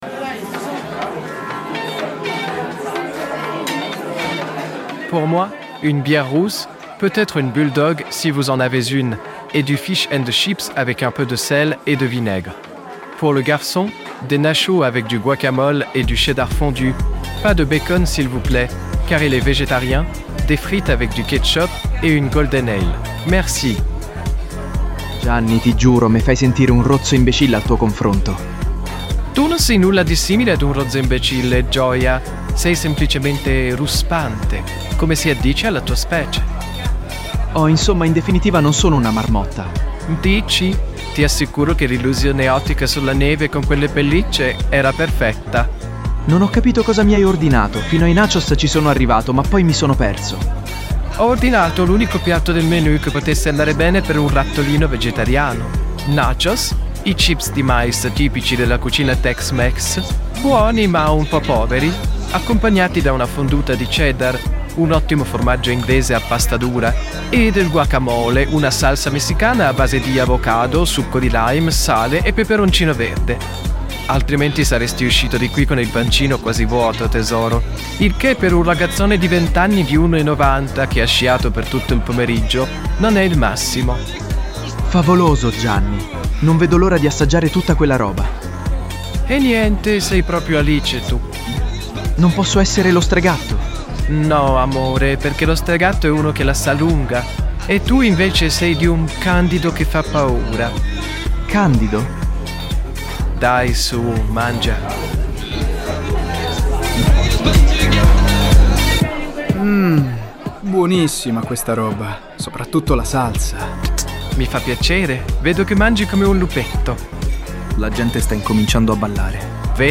Nel corso dell'episodio si possono ascoltare brani e cover tratti da "Music Sounds Better With You" (Stardust), "We Can Build A Fire" (Autoheart) e "Back" (Bad Boys Blue).
During the episode you can listen to songs and covers from "Music Sounds Better With You" (Stardust), "We Can Build A Fire" (Autoheart) and "Back" (Bad Boys Blue).